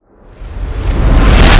implode_death.wav